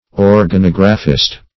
\Or`ga*nog"ra*phist\
organographist.mp3